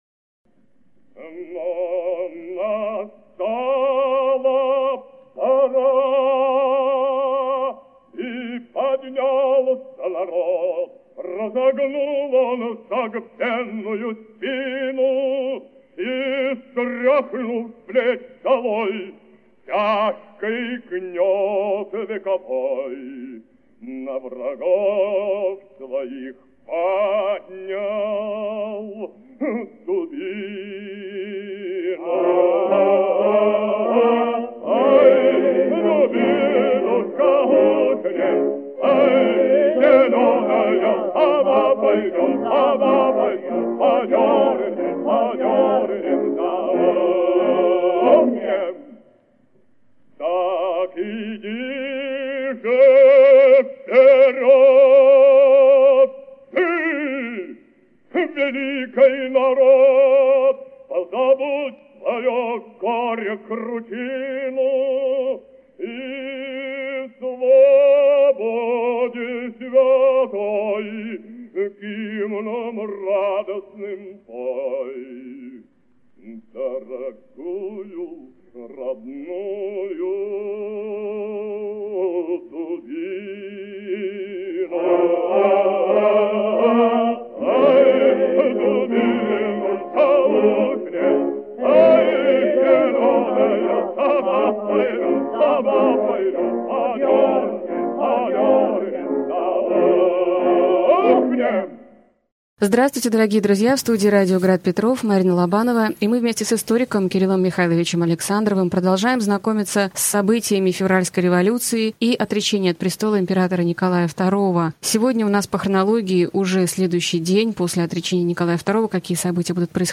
Аудиокнига Февральская революция и отречение Николая II. Лекция 39 | Библиотека аудиокниг